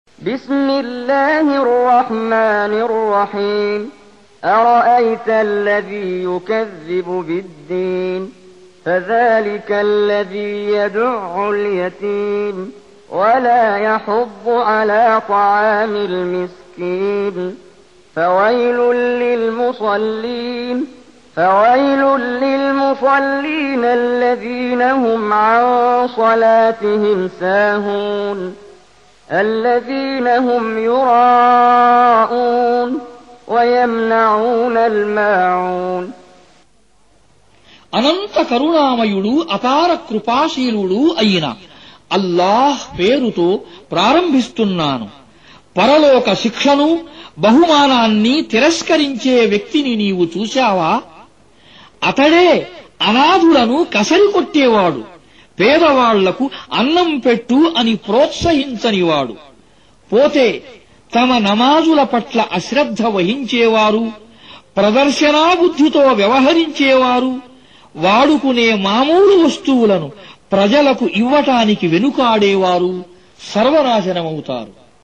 Recitation
Indian Telugu Translation With Qari Mohammed Jibreel